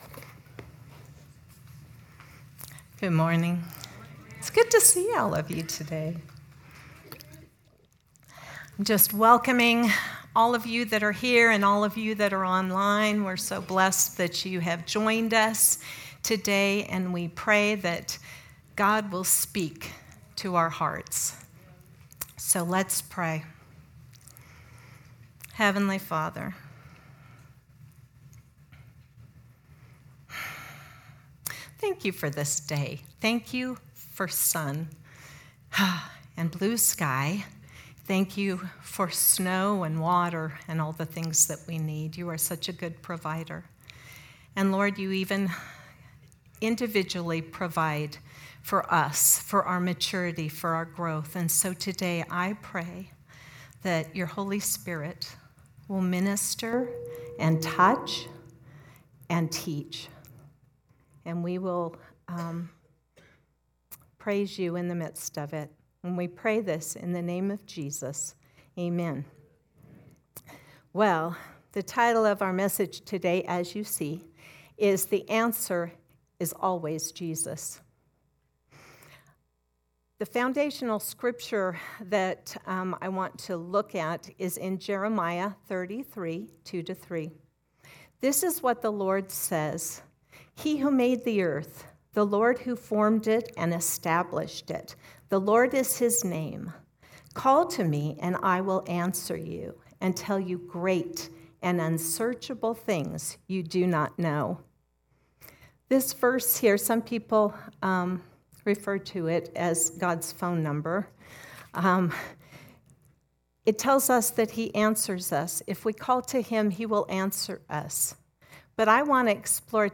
Sermons | The River Christian Church